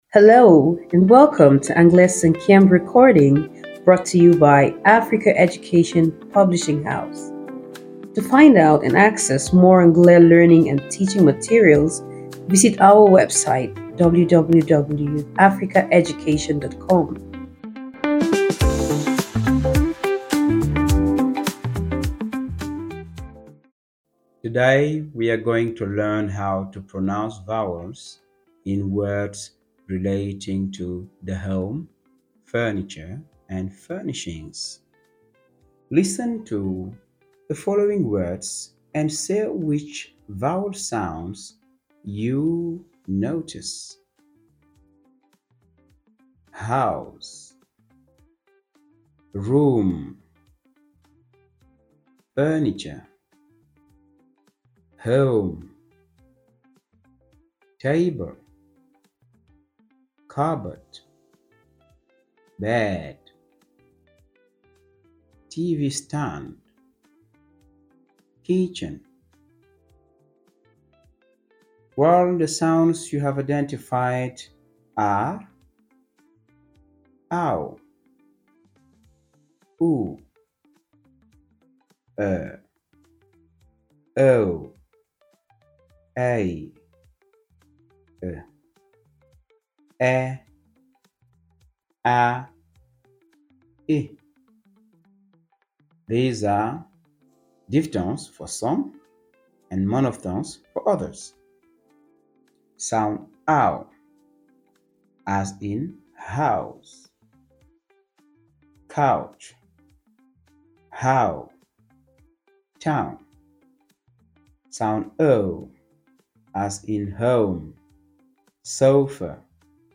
Speech work
DOL1_RLS2_speech-work-5e.mp3